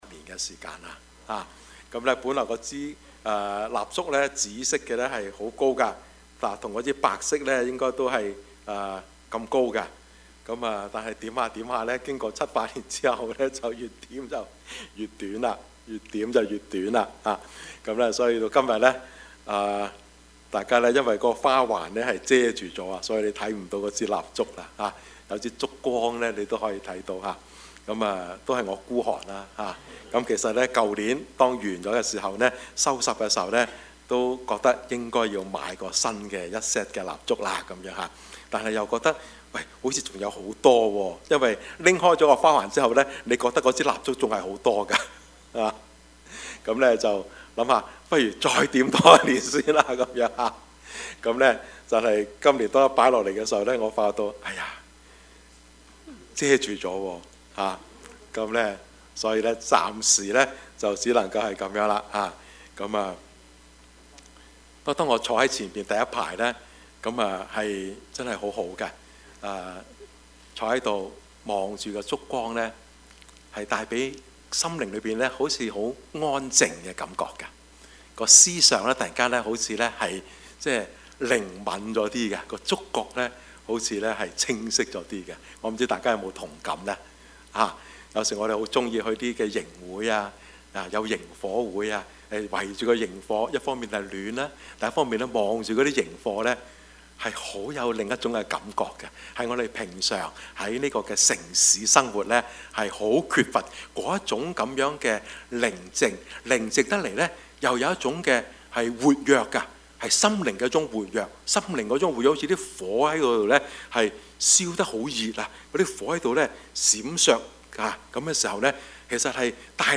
Service Type: 主日崇拜
Topics: 主日證道 « 2019年的感恩 蒙了憐憫 »